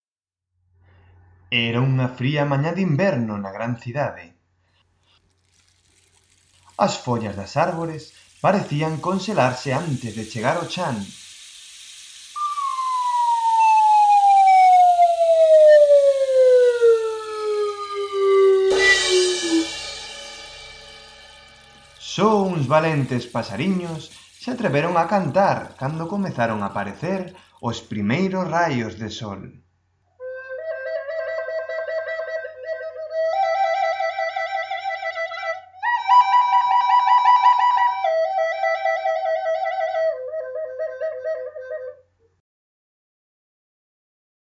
A continuación escoitaremos o conto sonoro "Paxariño de inverno", elaborado con instrumentos musicais e obxectos sonoros que podedes atopar na aula de música ou ben no noso contorno próximo.
CONTO_SONORO_PAXARINO_INVERNO.mp3